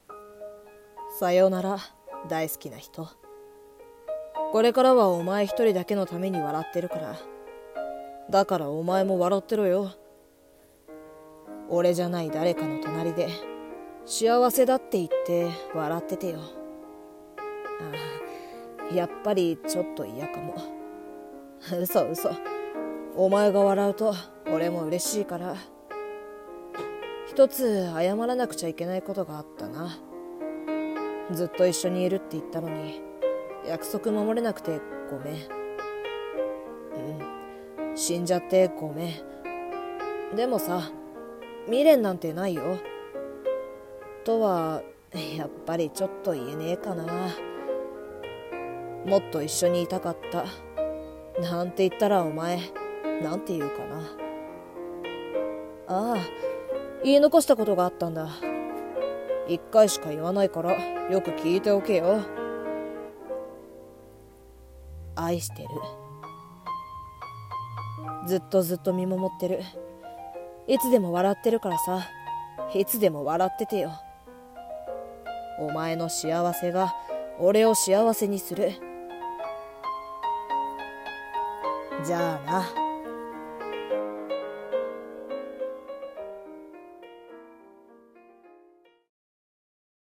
声劇「最期のメッセージ